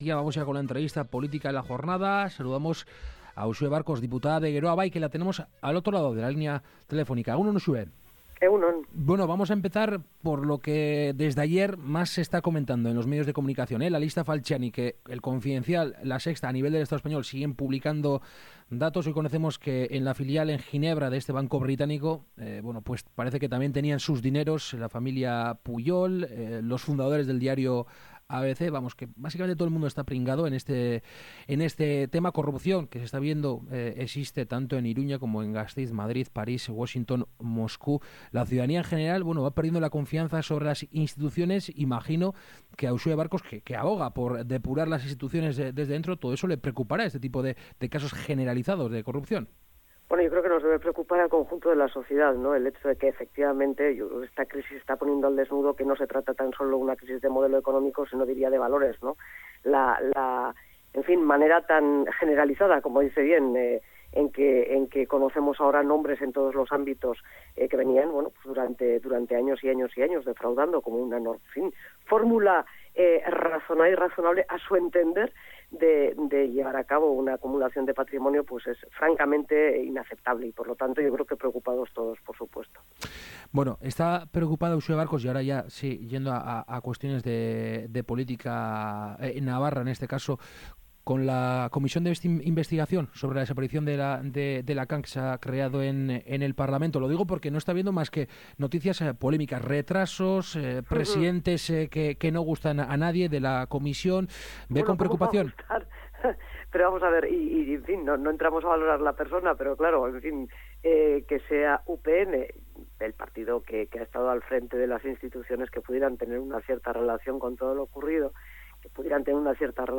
Esta mañana en Kalegorrian hemos entrevistado a la diputada de Gero Bai, Uxue Barkos. Entre otras cuestiones, Barkos ha valorado el pacto “antiyihadidsta” firmado por PP y PSOE y que hoy se debate en el Pleno del Congreso en Madrid.